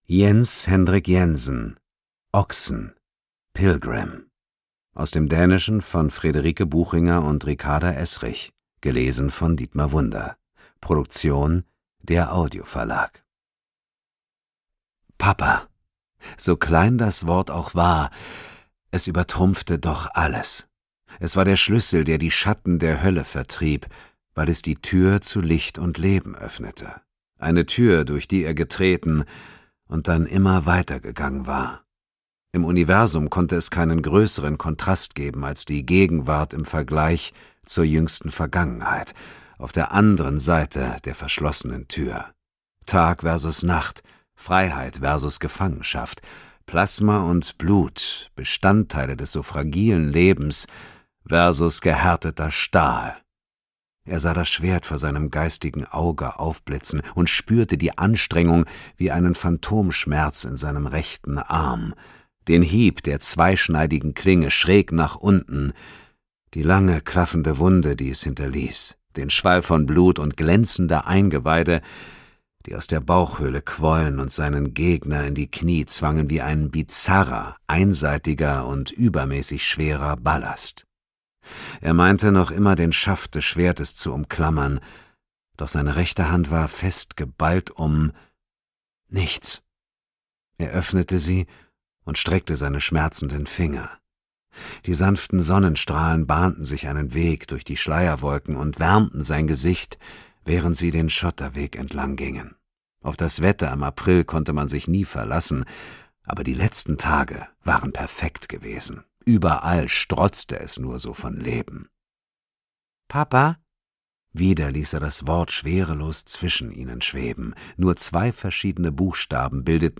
Ungekürzte Lesung mit Dietmar Wunder (2 mp3-CDs)
Dietmar Wunder (Sprecher)